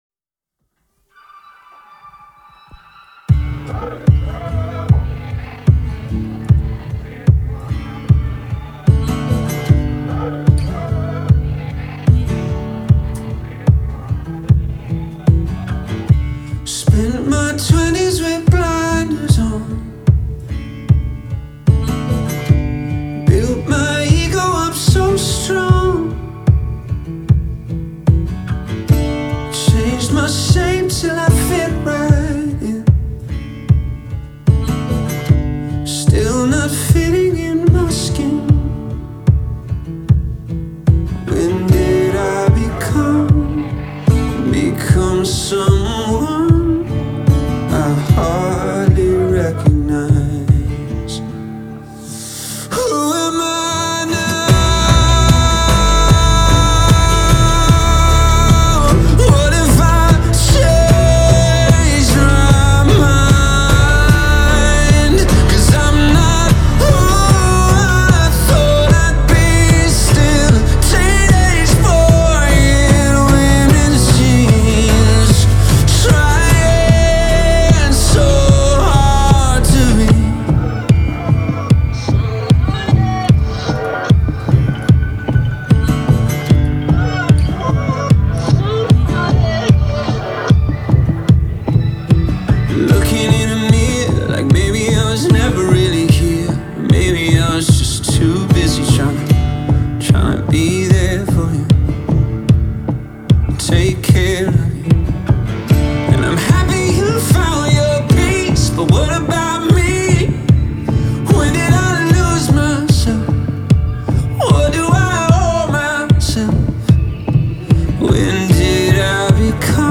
• Жанр: Rock